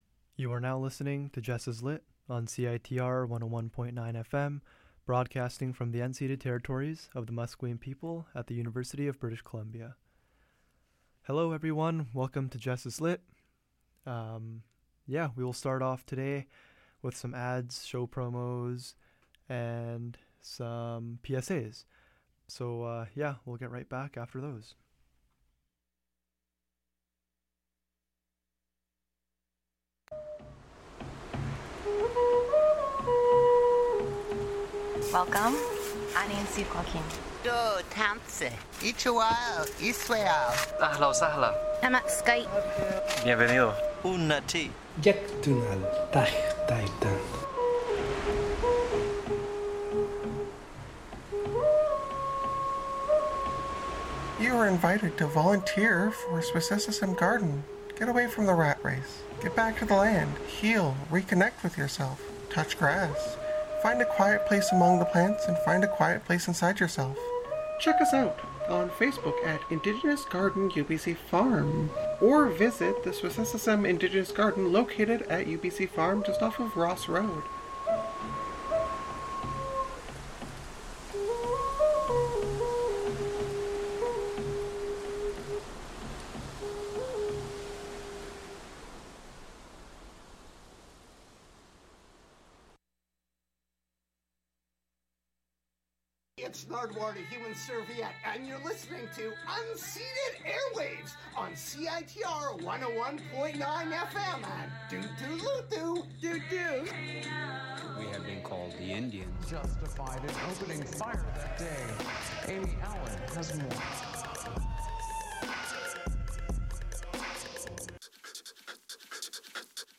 In the second half, I continue the of readings from the poetry collection, Love Poems: 2nd Edition by Nicholas Gordan, that explore various facets of love. The episode will conclude with a "surprise song."